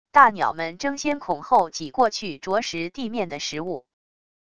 大鸟们争先恐后挤过去啄食地面的食物wav音频